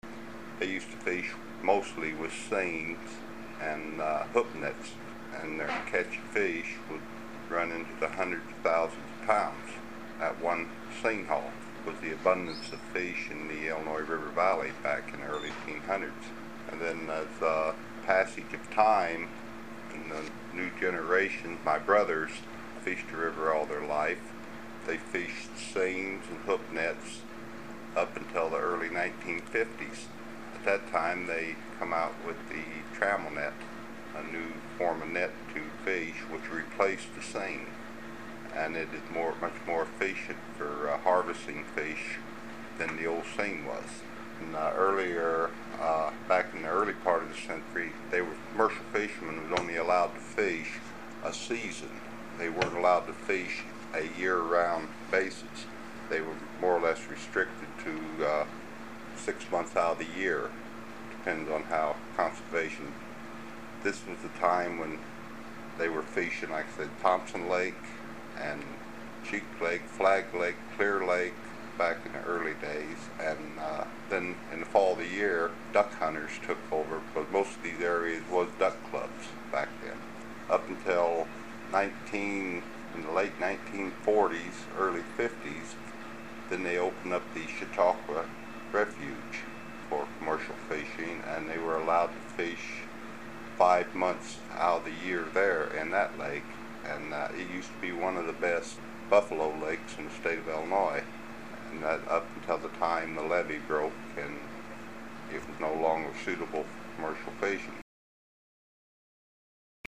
HTR Oral History, 08/18/1